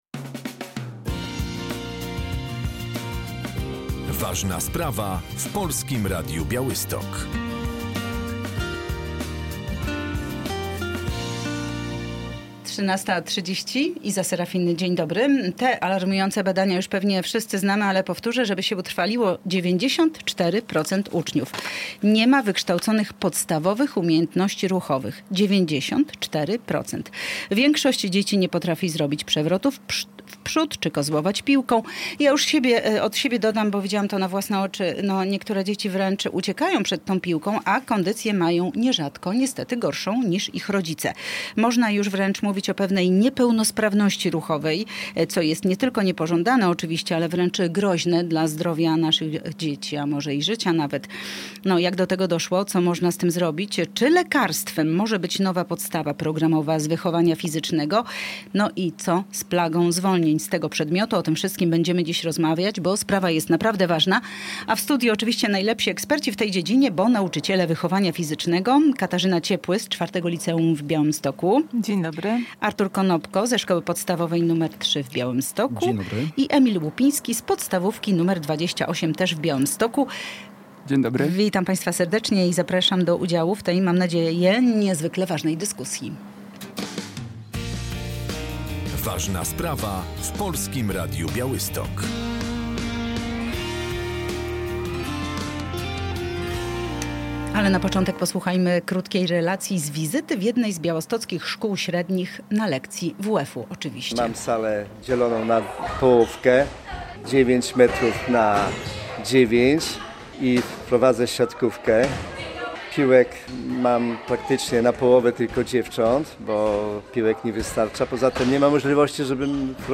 O to pytamy nauczycieli wychowania fizycznego.